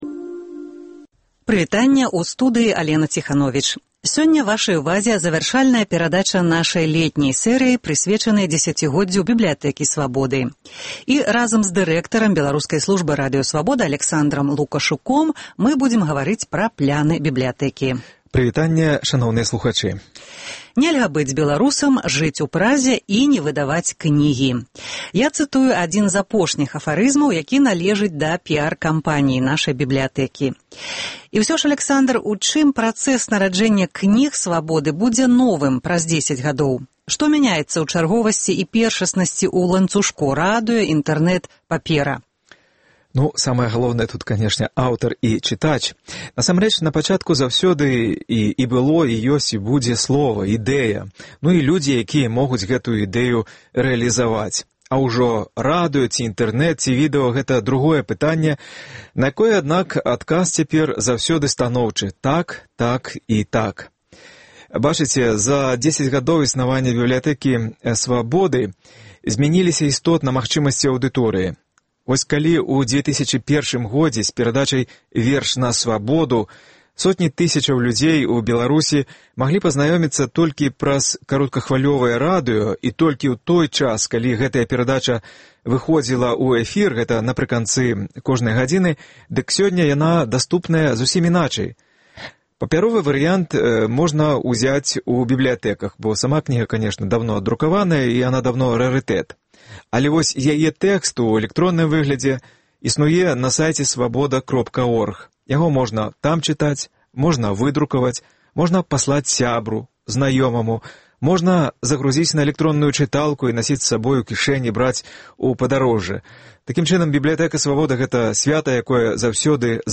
Пляны "Бібліятэкі Свабоды". Гутарка